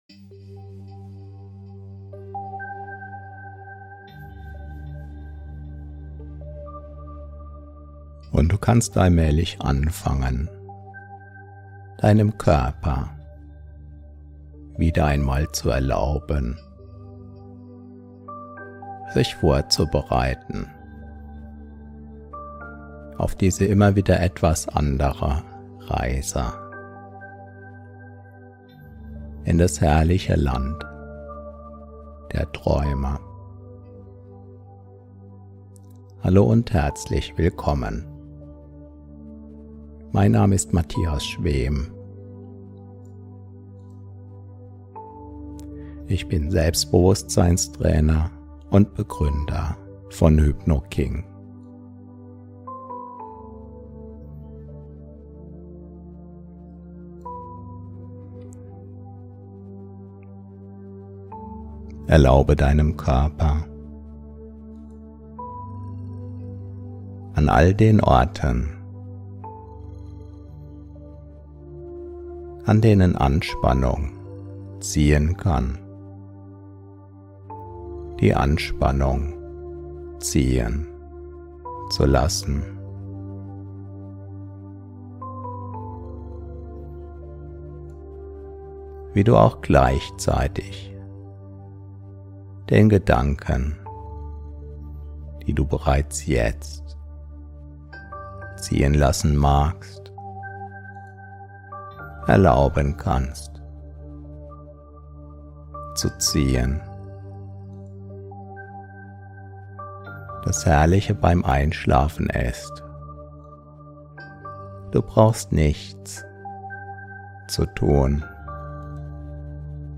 Diese geführte Hypnose hilft dir, Stress loszulassen und in einen tiefen, regenerierenden Schlaf einzutauchen. Entdecke, wie du mit jeder Einatmung entspannter wirst und sanft in das Land der Träume gleitest.